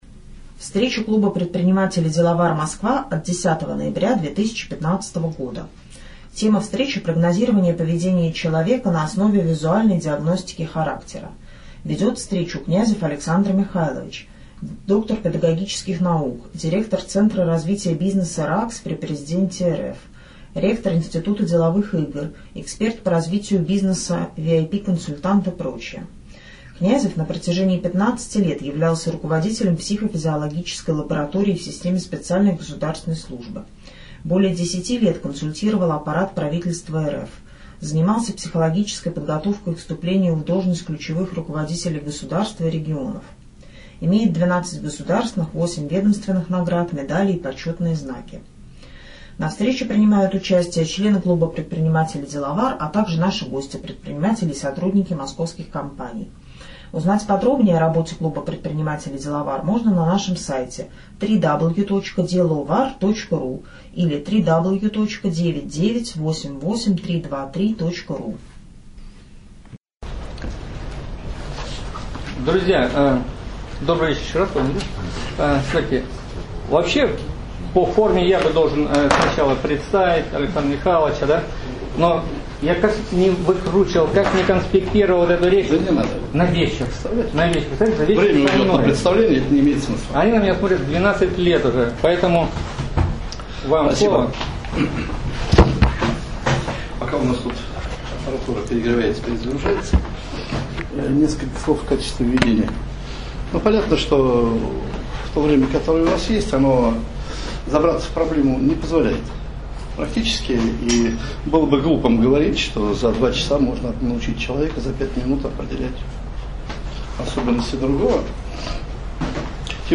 10 ноября 2015 года с 19:00 до 22:00 клуб предпринимателей "Деловар" провел очередную встречу клуба.